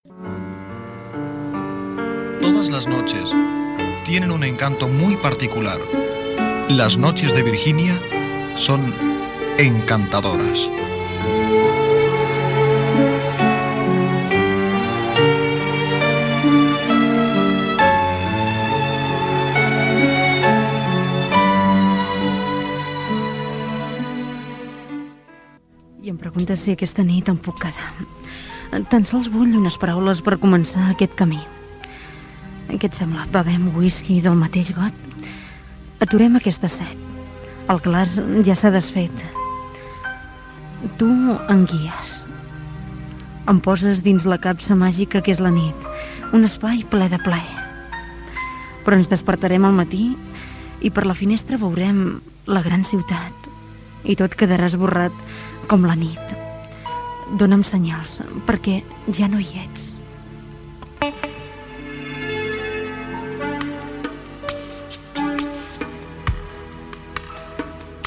Identificació del programa, comentari sobre la nit.
Musical
FM